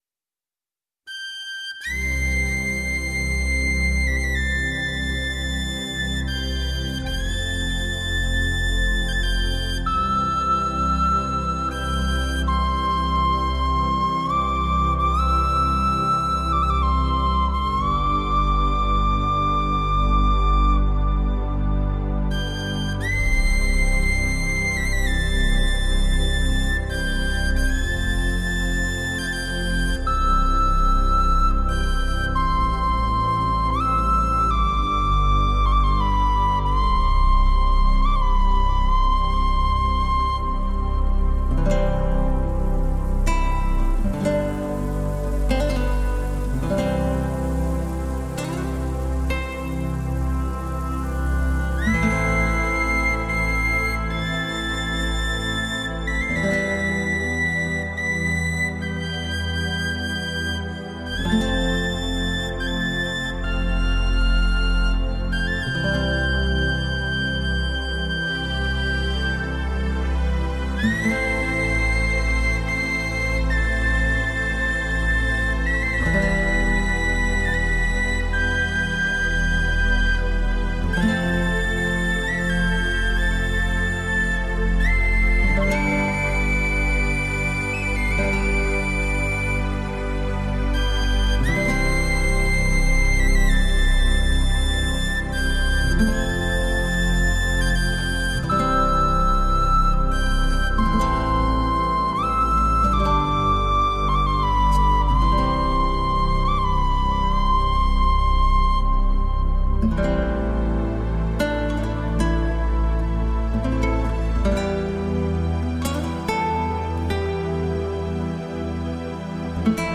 极具柔和动听，优美醉人的弦律，和绝妙的音色由如泡沫般细腻、
薄纱般绵密的声响所纺织而成，在空气中漫漫弥散让你沉静其中……
和声、新颖的配器、丰富的音色、浓郁的浪漫情调给人以全新的感受，形成了特有的美感。